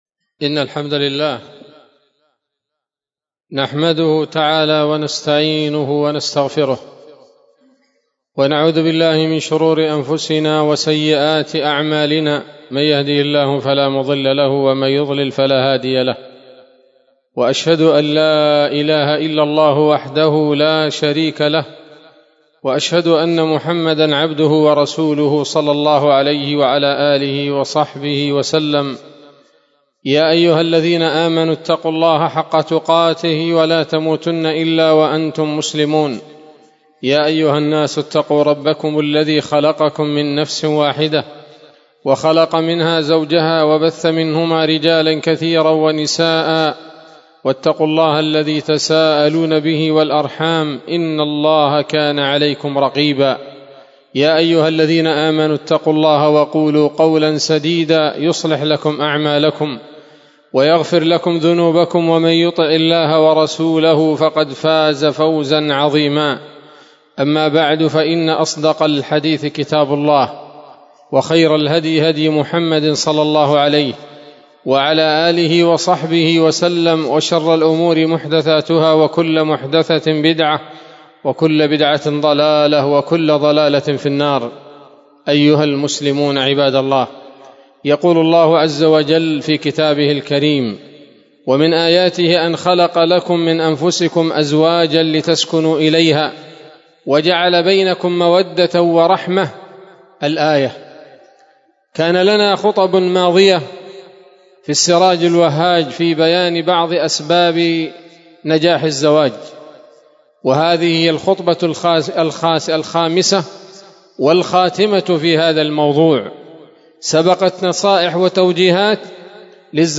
خطبة جمعة بعنوان: (( السراج الوهاج في بيان أسباب نجاح الزواج [5] )) 16 جمادى الأولى 1447 هـ، دار الحديث السلفية بصلاح الدين